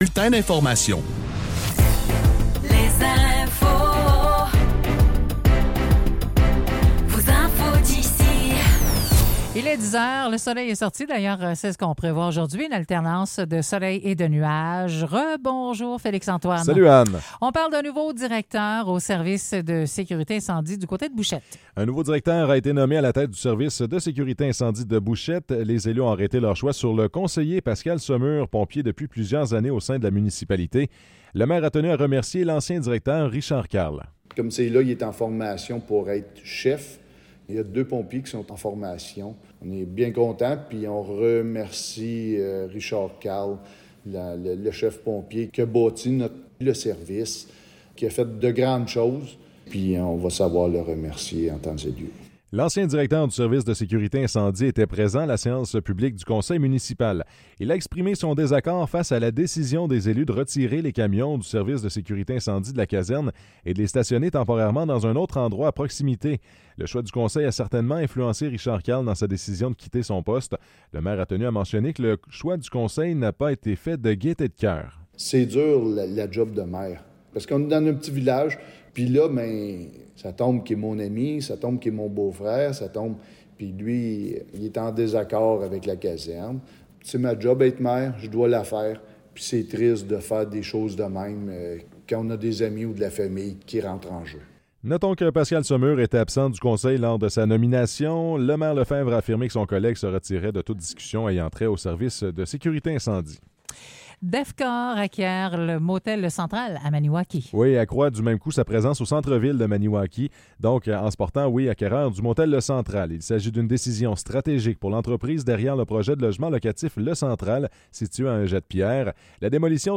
Nouvelles locales - 18 novembre 2024 - 10 h